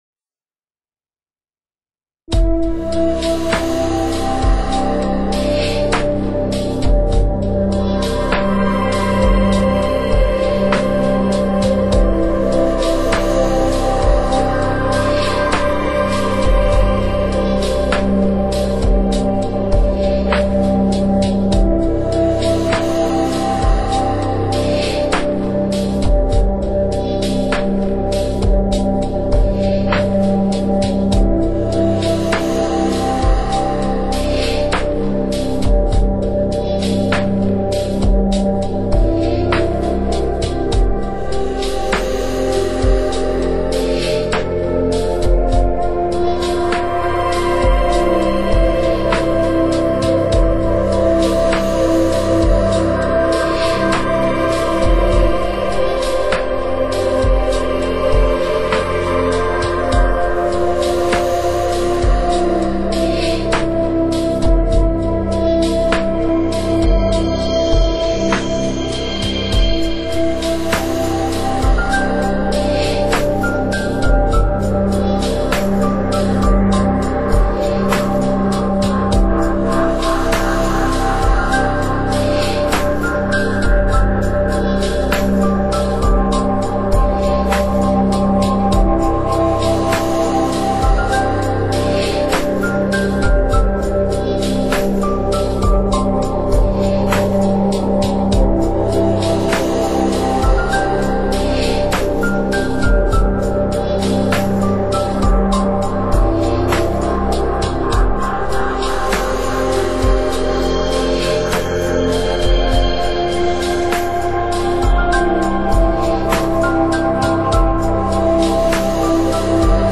【音乐类型】：NEW AGE